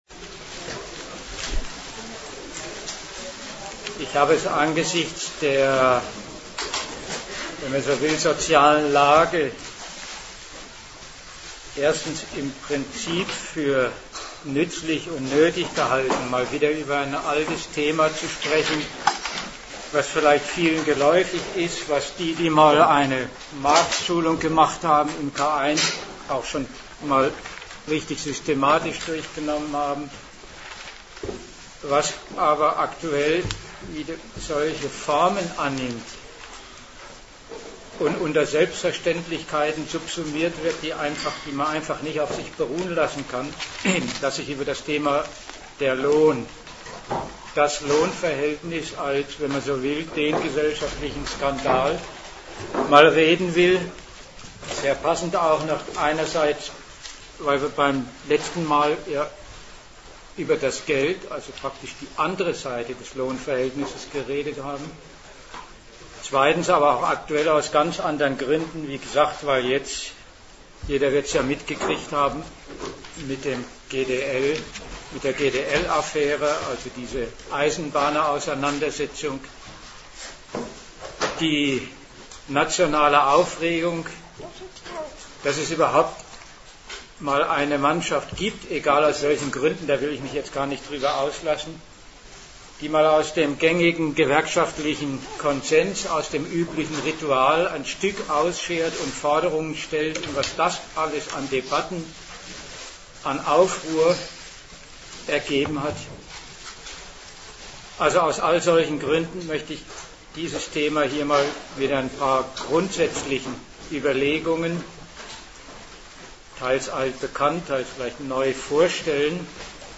Datum 27.09.2007 Ort München Themenbereich Arbeit, Kapital und Sozialstaat Dozent Gastreferenten der Zeitschrift GegenStandpunkt Um den Lohn haben die, die von ihm leben müssen, einmal gekämpft, um von ihm leben zu können.